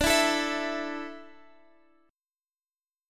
A5/D chord